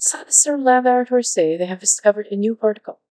coqui-tts - a deep learning toolkit for Text-to-Speech, battle-tested in research and production